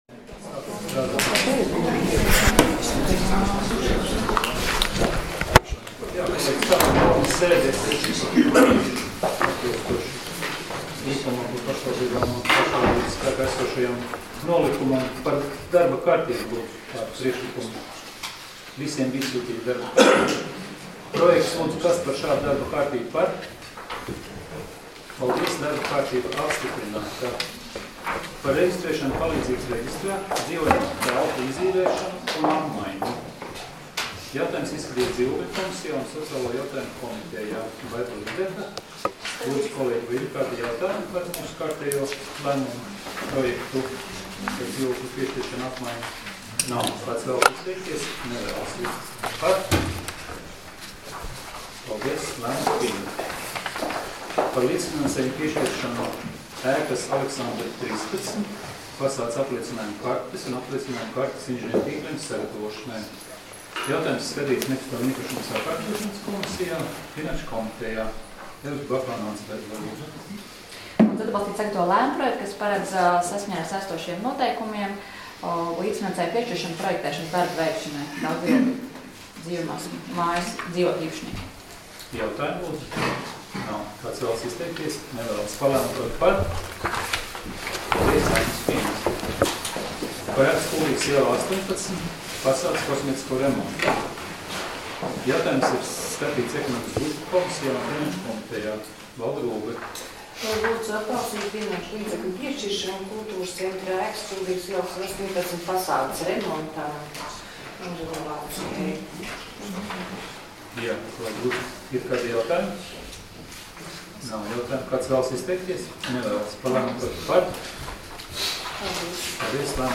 Domes sēdes 13.04.2018. audioieraksts